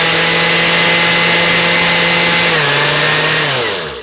blender.mp3